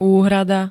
Zvukové nahrávky niektorých slov
jxpc-uhrada.ogg